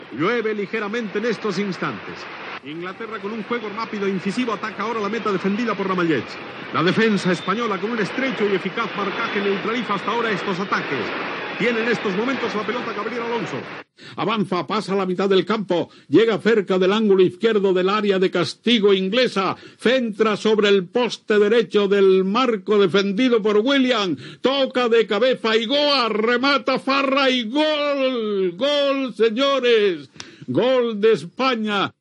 Recreació de l'inici del partit: àrbitre i aliniacions de les dues seleccions.
Tres recreacions diferents de la jugada fetes per Matías Prats, en no haver cap enregistrament original.
Esportiu